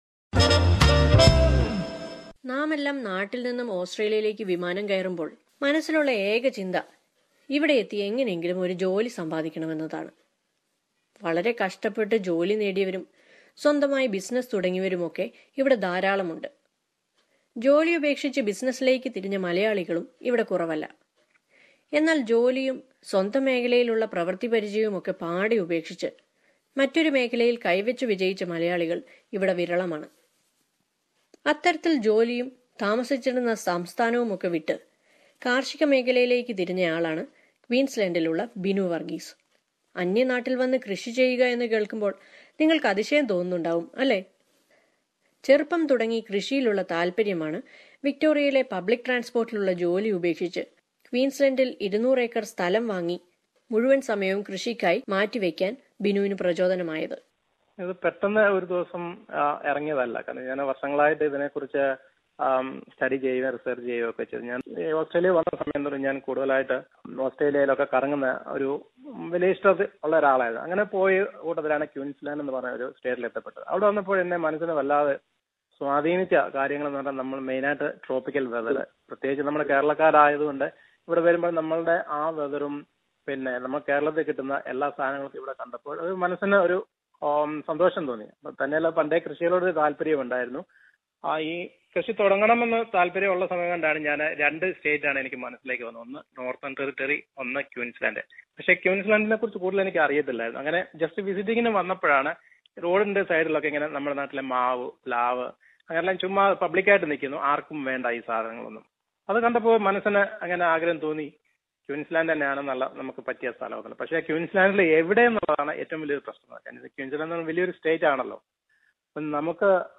Here are a few people who quit their job to try their hands in agriculture. Let us listen to a report on their experiences with 200 acres of established banana plantation in Northern Queensland...